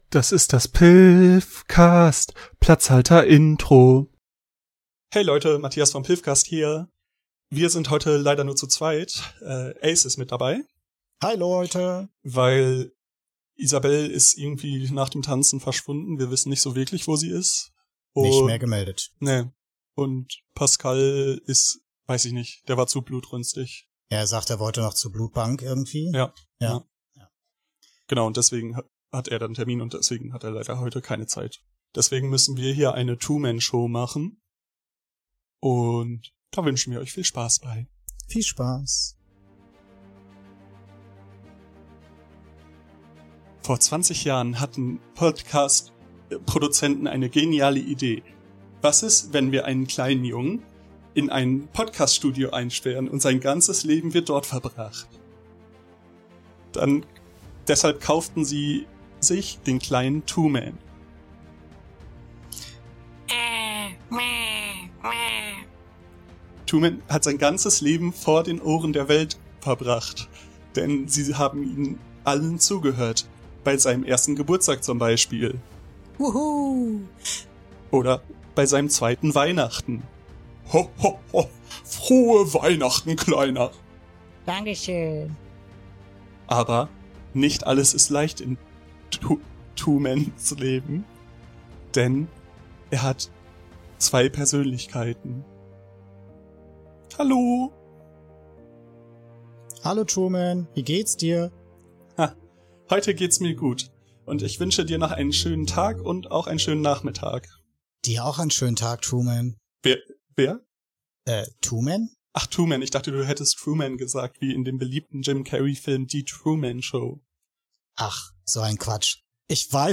Wir sind nur zu zweit, darum machen wir eine Twoman Show. Doch werden wir herausfinden, dass das alles nicht echt ist?